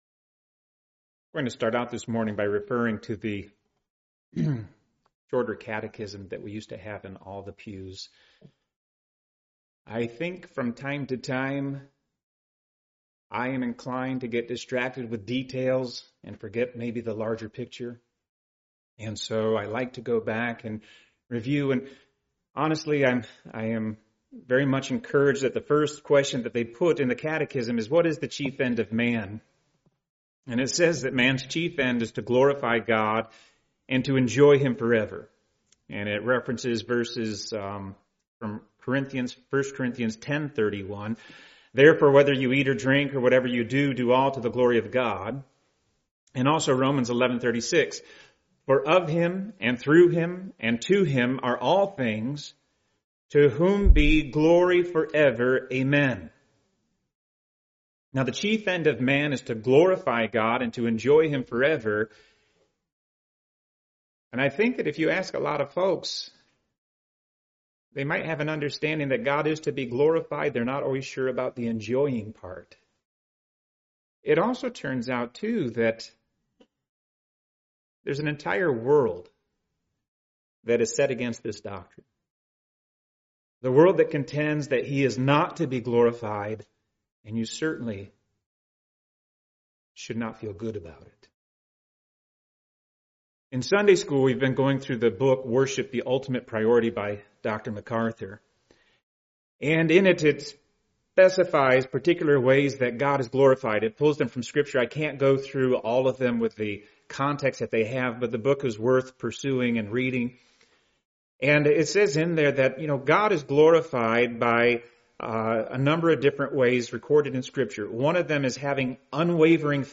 Part of the New Testamanet series, preached at a Morning Service service.